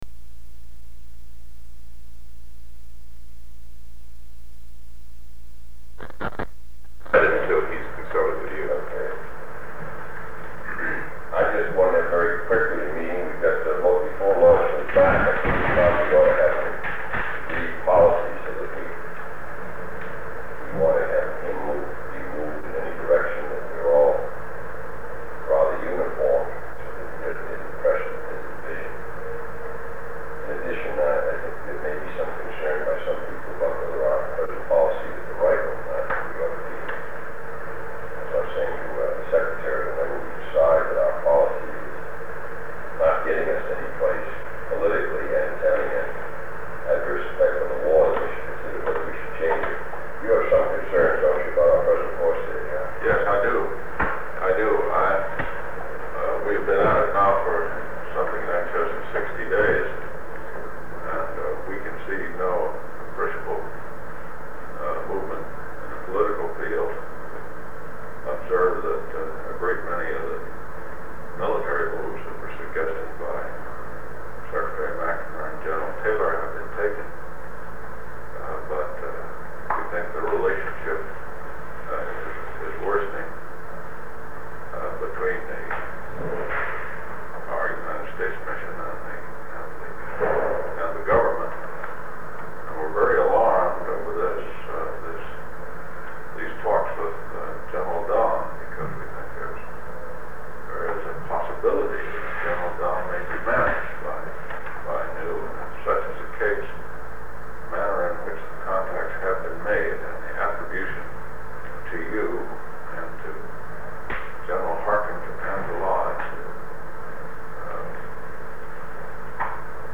Sound recording of a meeting about Vietnam held on October 25, 1963, between President John F. Kennedy, Special Assistant to the President for National Security Affairs McGeorge Bundy, Secretary of Defense Robert S. McNamara, Director of the Central Intelligence Agency (CIA) John McCone, and Attorney General Robert F. Kennedy.
Topics include an intelligence report on Vietnam, United States Embassy staffing in South Vietnam, planning, and instructions for United States Ambassador to South Vietnam Henry Cabot Lodge. Seven segments of the recording totaling 1 minute and 35 seconds have been removed in accordance with Section 3.4 (b) (1), (3) of Executive Order 13526.